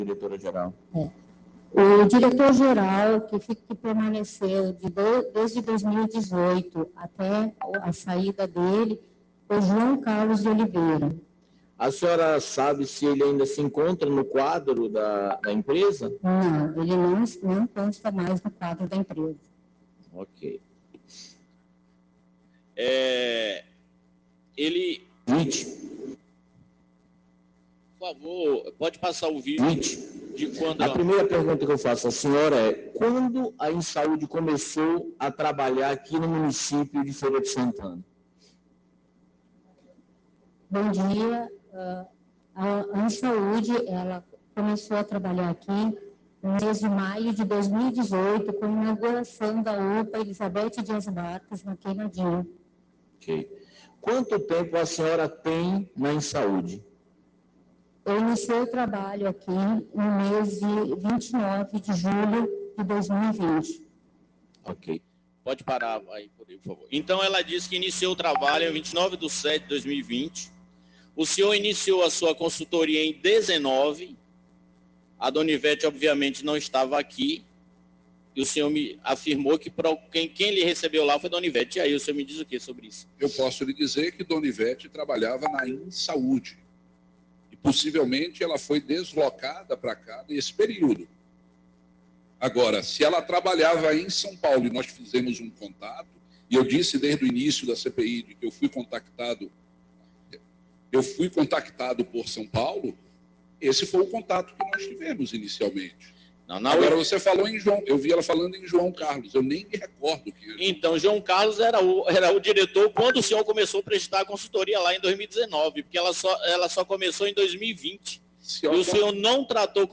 O secretário foi desmoralizado no plenário da Câmara ao mentir no depoimento da CPI nesta terça-feira (12).
Ouçam os áudios no momento em que Dr. Marcelo teria mentido na CPI e foi duramente advertido pelos edis.